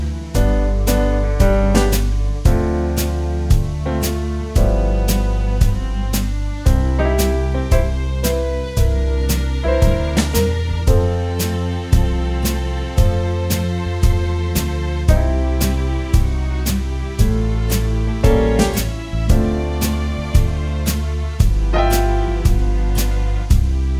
no Backing Vocals Jazz / Swing 3:28 Buy £1.50